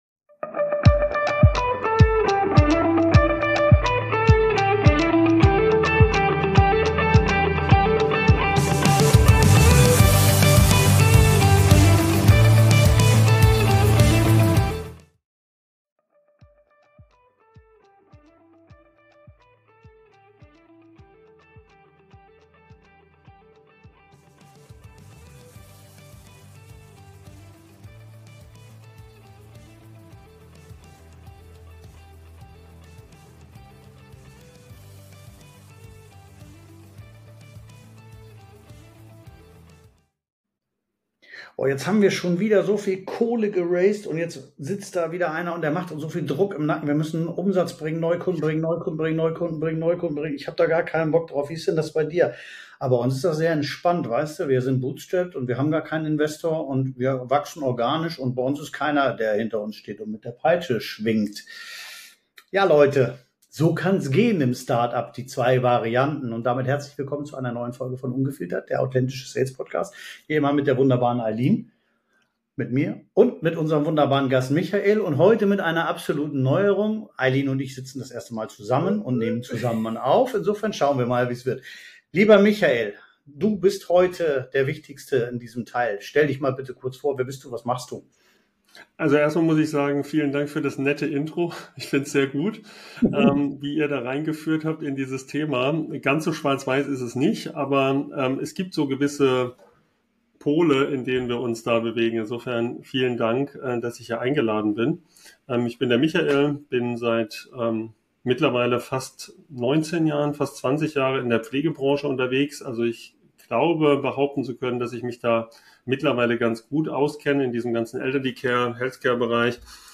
Real Talk über Start-ups